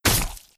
Melee Weapon Attack 28.wav